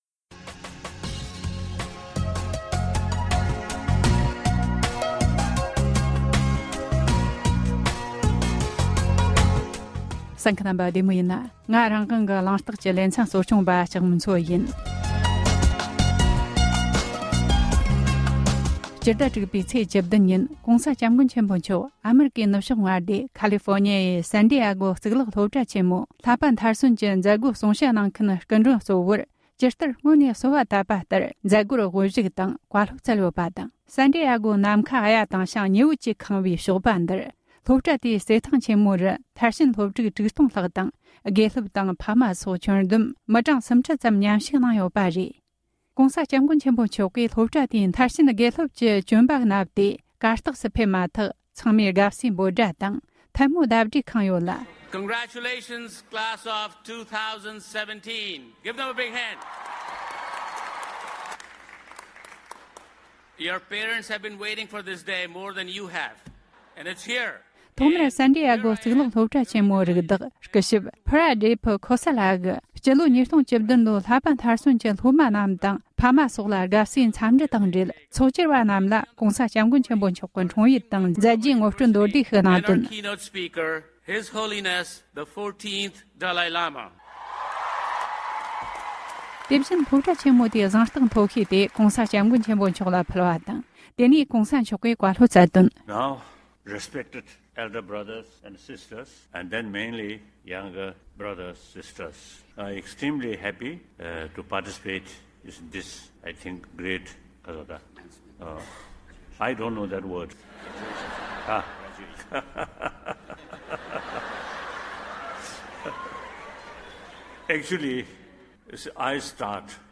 ཨ་རིའི་སན་ཌིཡེགྷོའི་གཙུག་ལག་སློབ་གྲྭ་ཆེན་མོའི་བསླབ་པ་མཐར་སོན་གྱི་མཛད་སྒོའི་སྐབས་བཀའ་སློབ་སྩལ་བ།